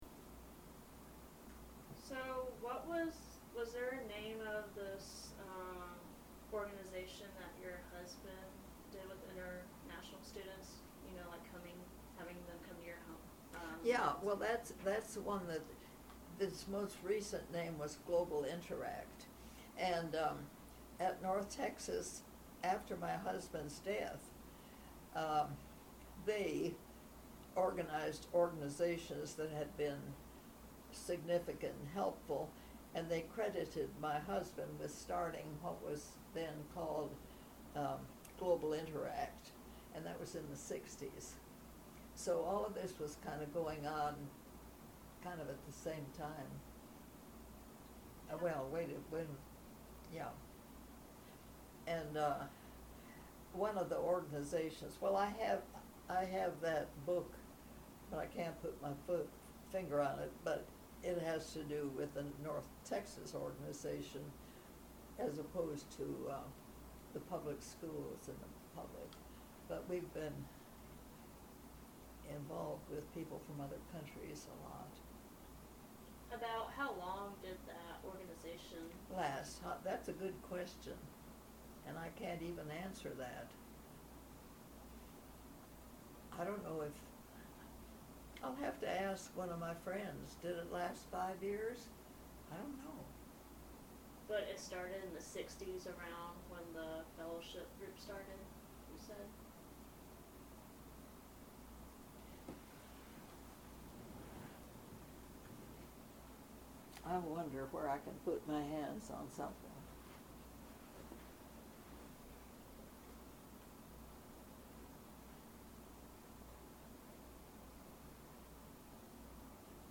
Excerpt of an Oral History Interview